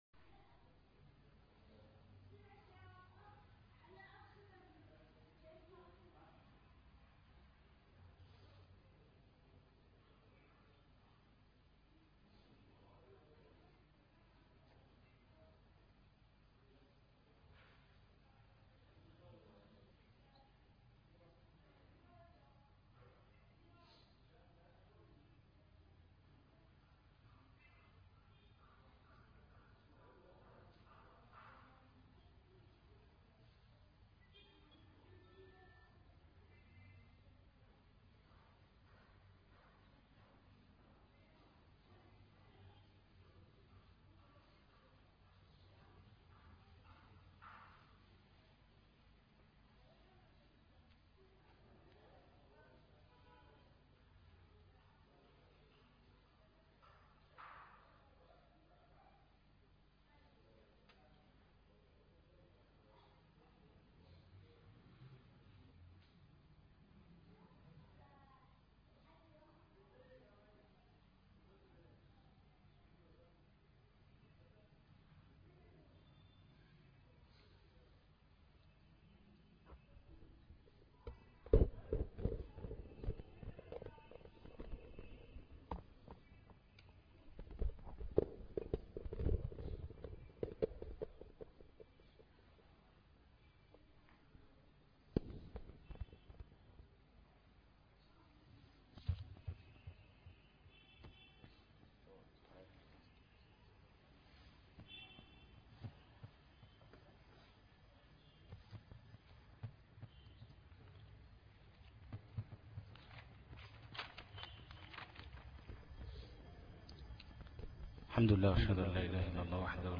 دروس التجويد 1 - الفرقة التمهيدية - الشيخ أبو إسحاق الحويني